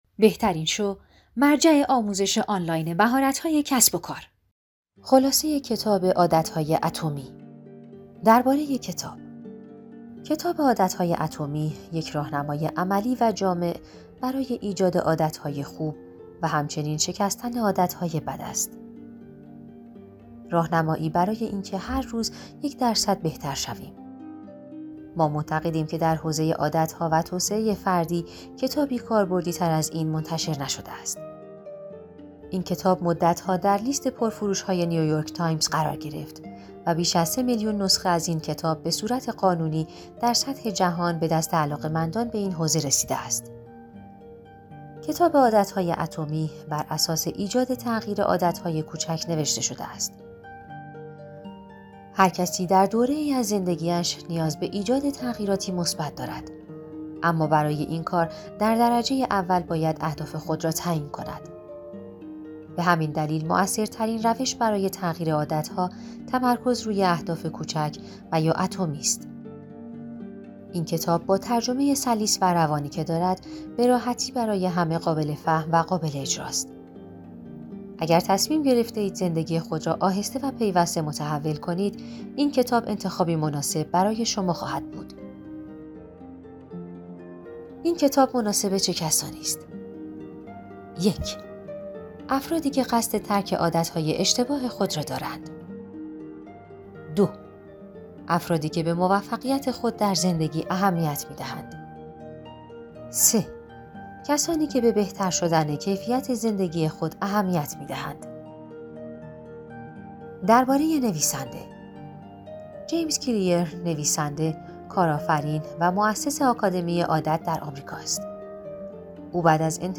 کتاب صوتی موجود است